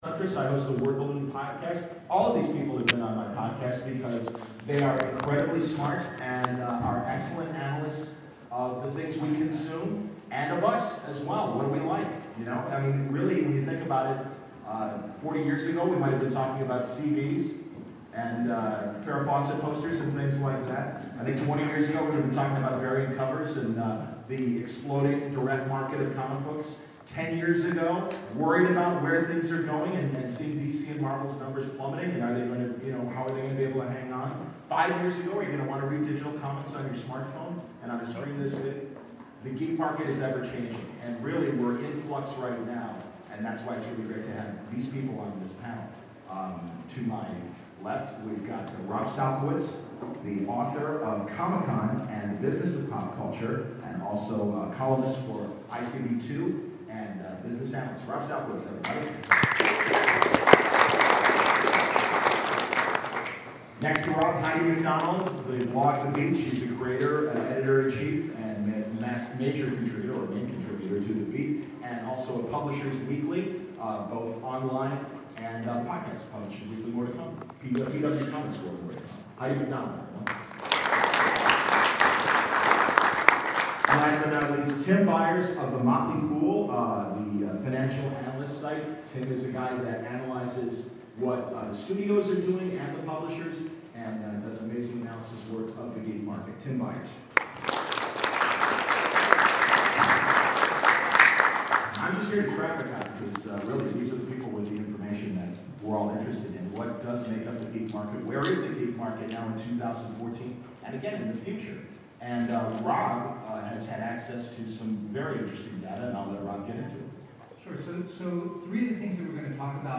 The Future of Geek: San Diego Comic Con 2014 Panel was held Friday July 25th at the San Diego convention center in room 28DE.